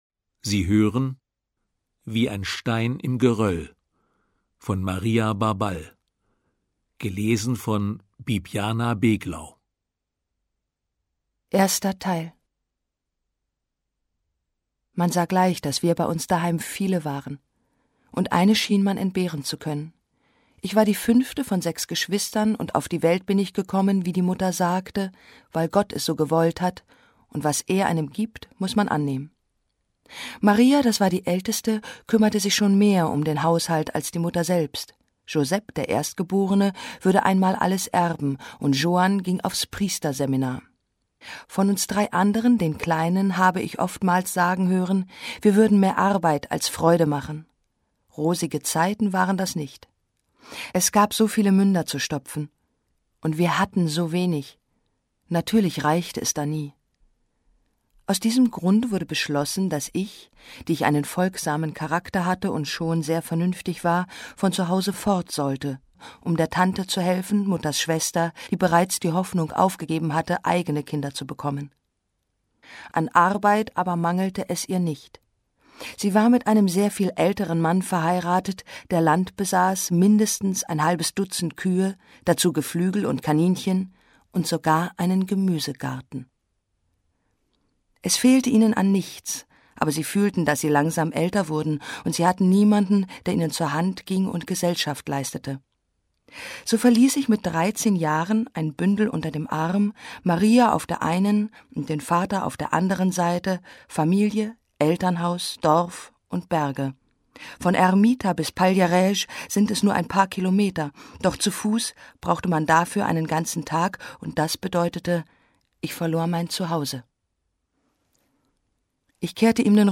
Ungekürzte Lesung mit Bibiana Beglau (1 mp3-CD)
Bibiana Beglau (Sprecher)